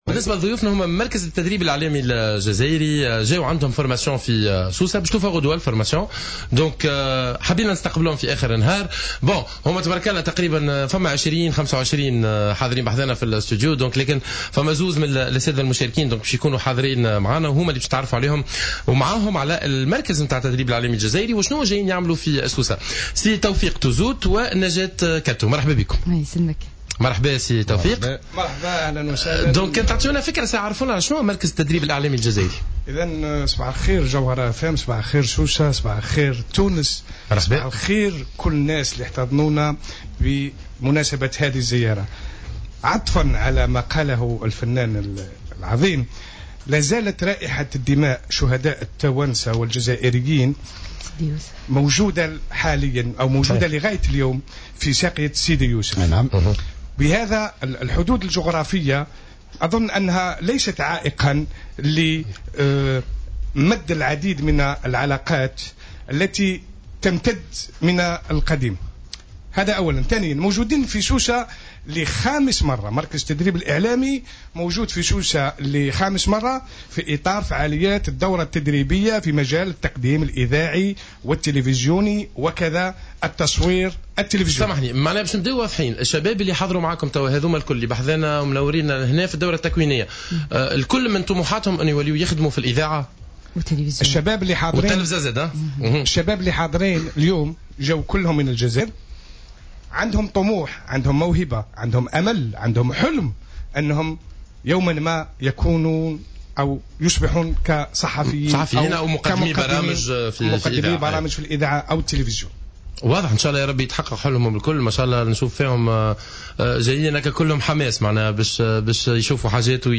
استقبلت الجوهرة اف ام اليوم في برنامج صباح الورد مجموعة من المشاركين الجزائريين...